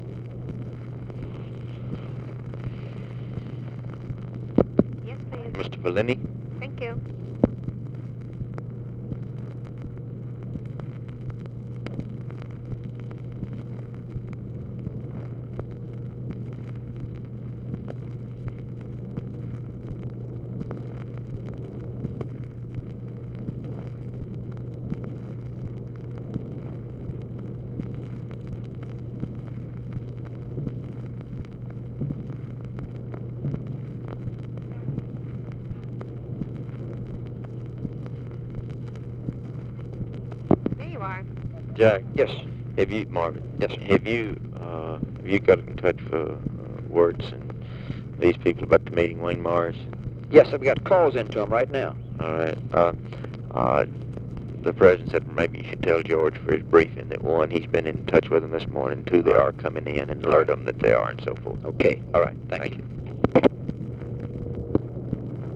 Conversation with JACK VALENTI
Secret White House Tapes